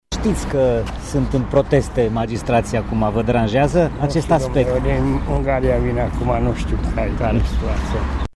Chiar dacă magistrații de la Tribunalul Mureș protestează încă din 21 ianuarie, unii oameni nu știau: